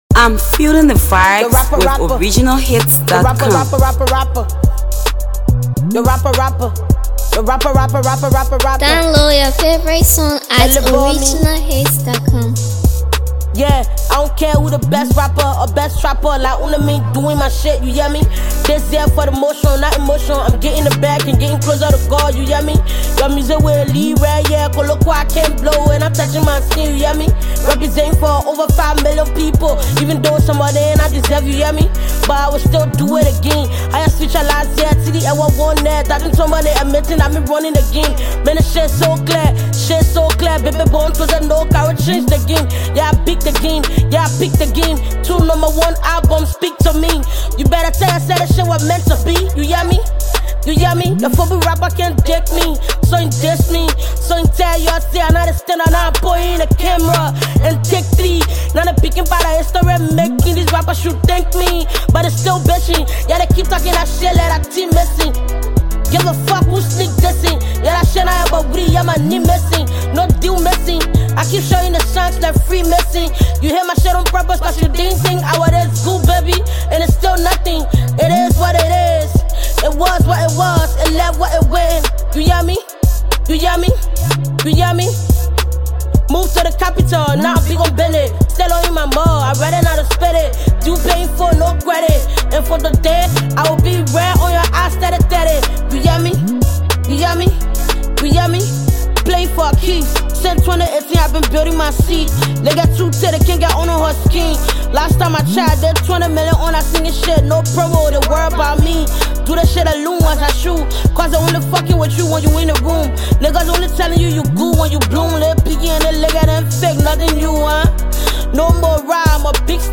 female rapper
blazing new track